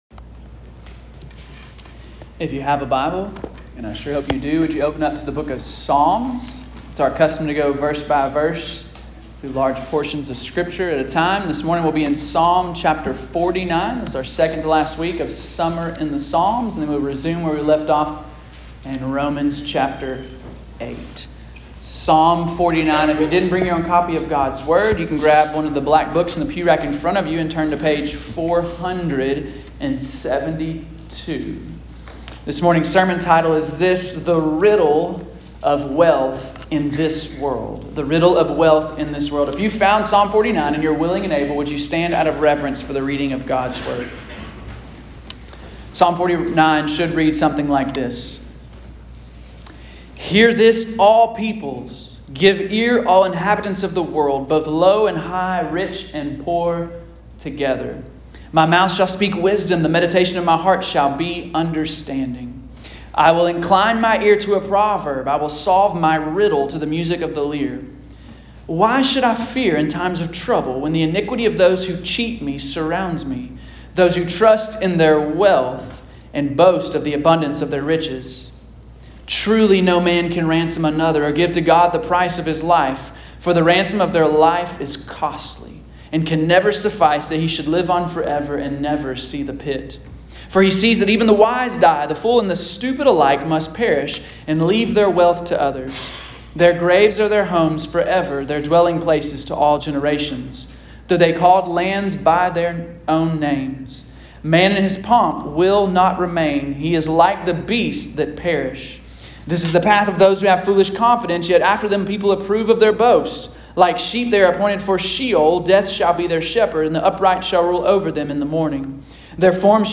Bethany Baptist Church Listen to Sermons